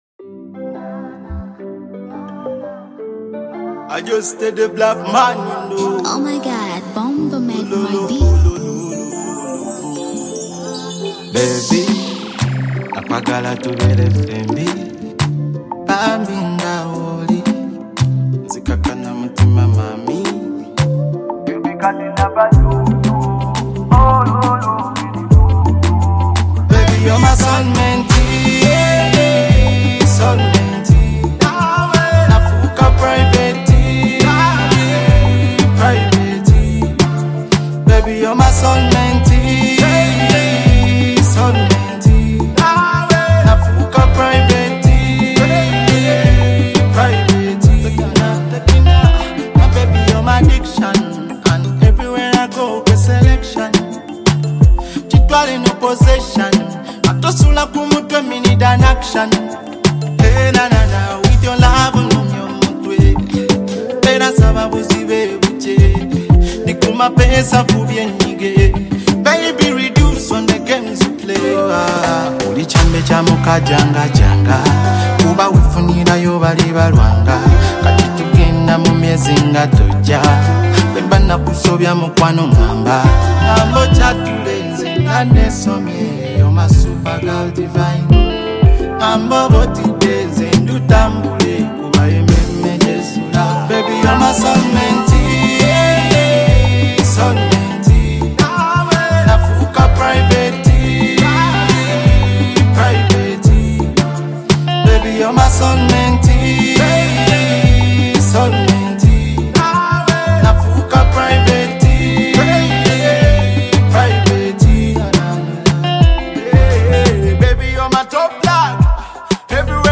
love song
With a blend of Afrobeat rhythm and soulful melodies